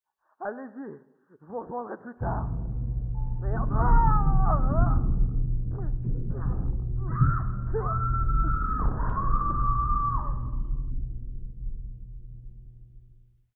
Commotion19.ogg